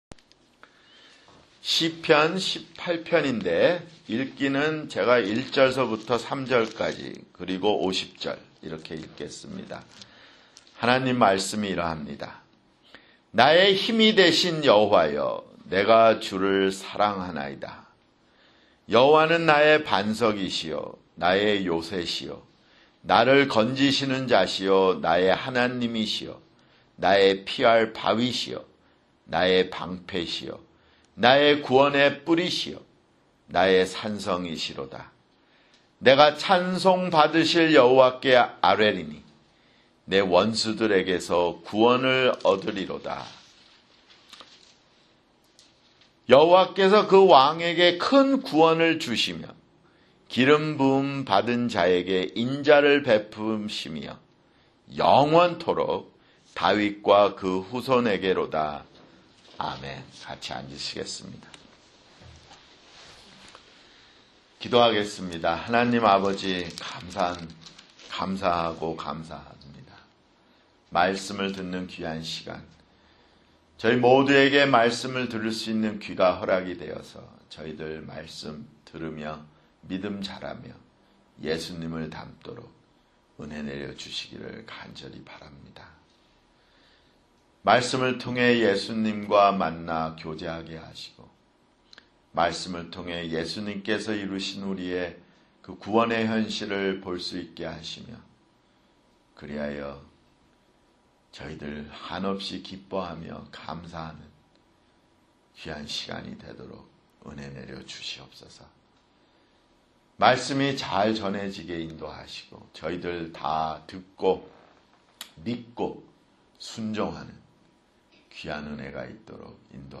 [주일설교] 시편 (16)